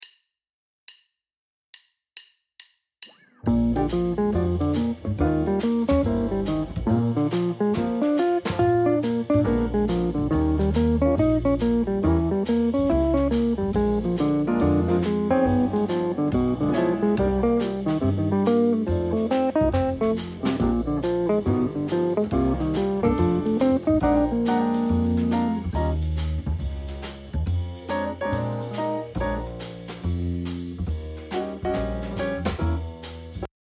• 1 Jazz Swing Backing Track